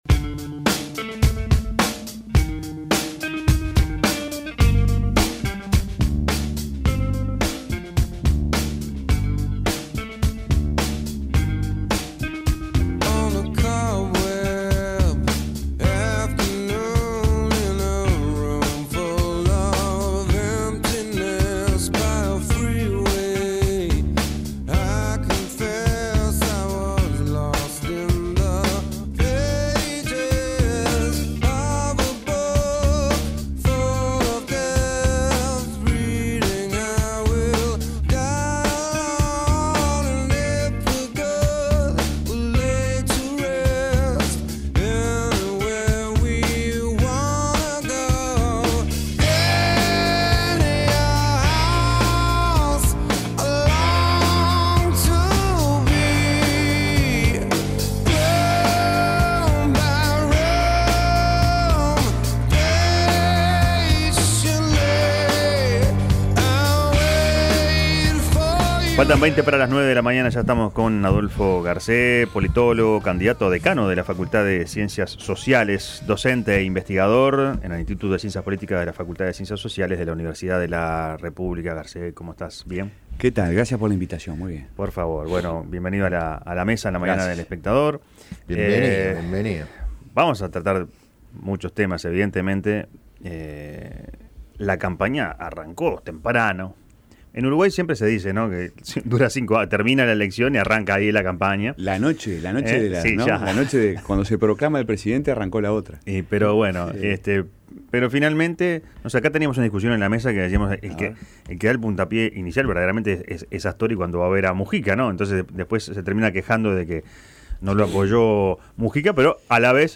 Descargar Audio no soportado Escuche la entrevista completa: Descargar Audio no soportado